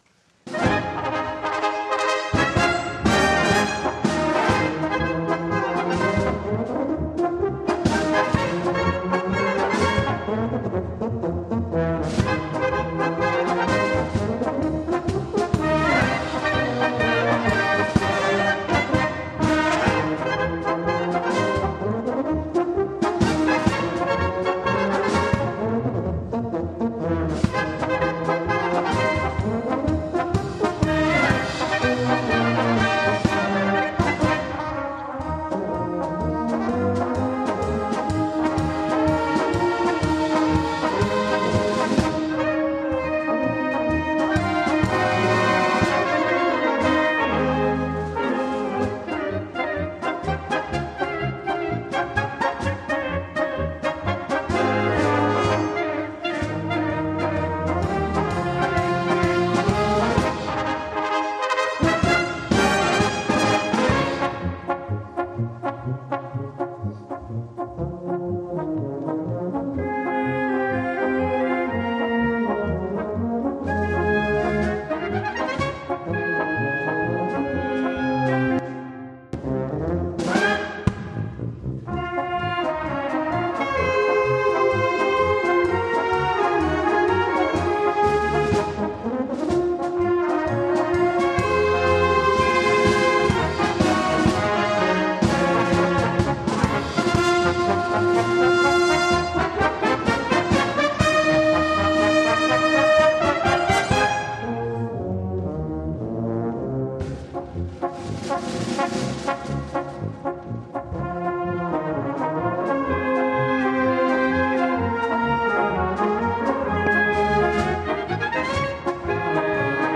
Hörprobe vom Jahreskonzert 2024
Hier eine kleine Hörprobe vom Jahres-Konzert 2024 im Forum der Gesamtschule in Havixbeck